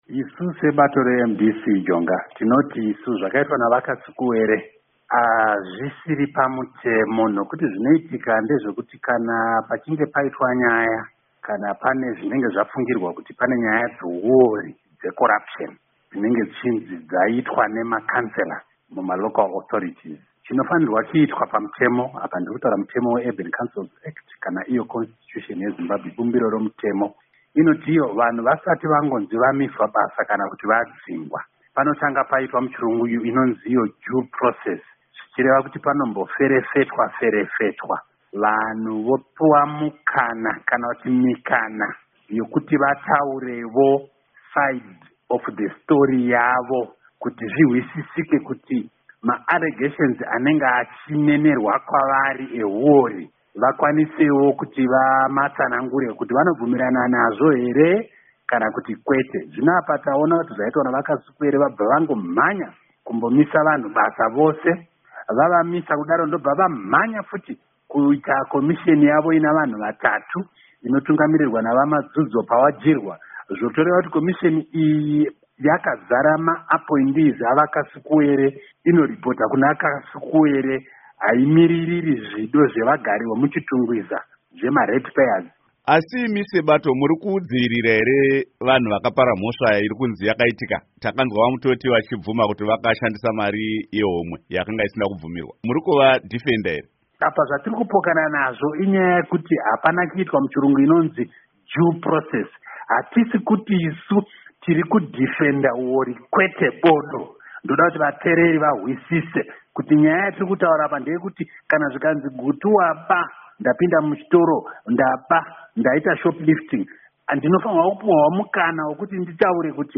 Hurukuro naVaObert Gutu